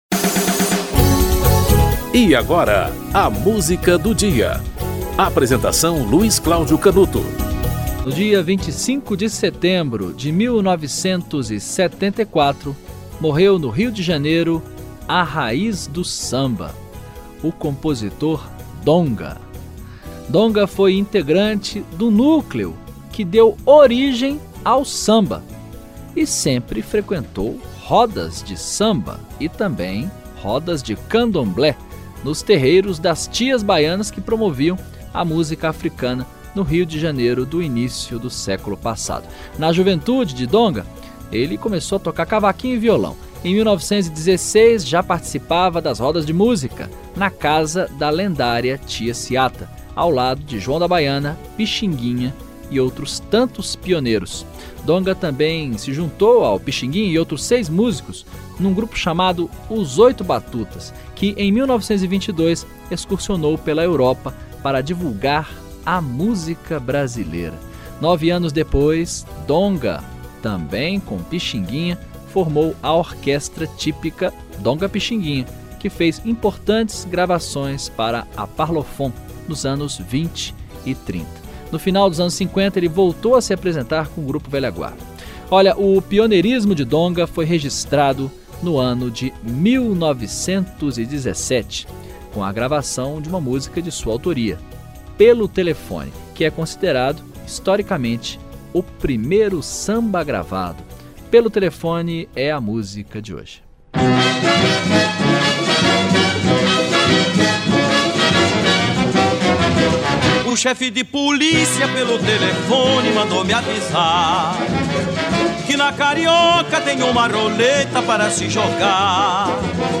Gilberto Alves - Pelo Telefone (Donga e Mauro de Almeida)
O programa apresenta, diariamente, uma música para "ilustrar" um fato histórico ou curioso que ocorreu naquele dia ao longo da História.